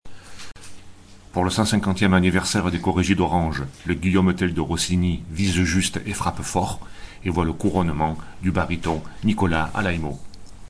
A tout seigneur, tout honneur, Nicola Alaimo, retrouve dans le rôle-titre, tout ce qui fait son succès interplanétaire : ton juste de ce révolté populaire au cœur droit, voix au timbre sombre qui dit sa détermination et son engagement dramatique, son large phrasé déployant quant à lui une profonde dimension héroïque. Dans un français irréprochable, Nicola se joue sans vergogne des fa et sol aigus qui parsèment sa partition.